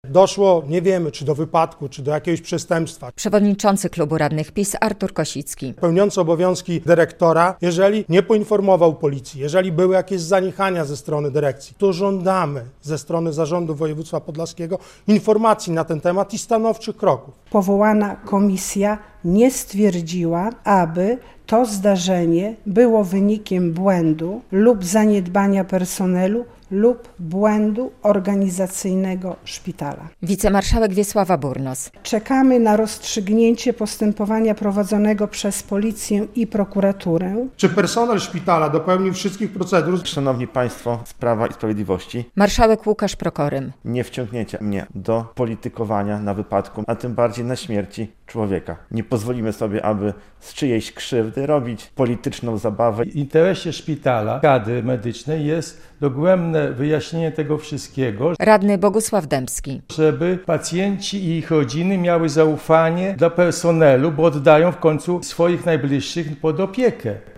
Tymi tematami zajmowali się we wtorek (18.02) podlascy radni na nadzwyczajnej sesji sejmiku.
relacja